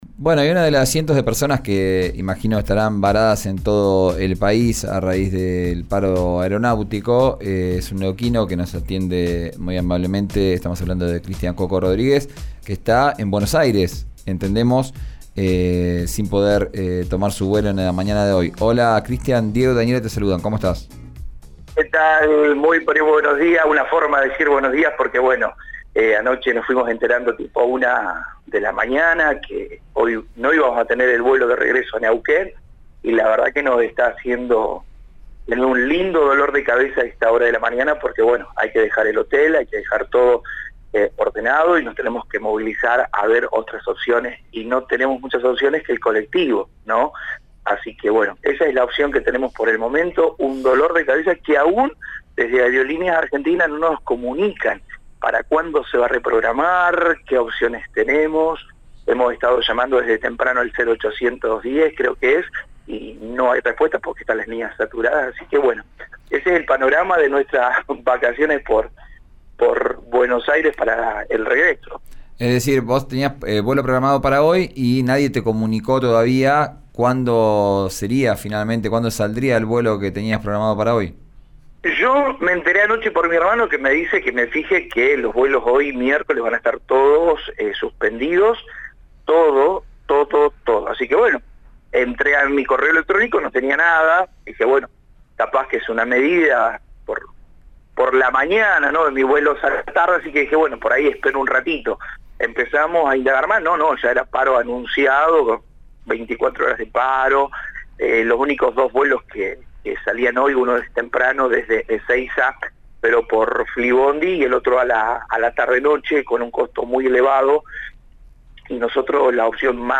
«No hay respuestas y las líneas están saturadas», manifestó a RÍO NEGRO RADIO, uno de los varados que tenía programado su vuelo a Neuquén para esta tarde.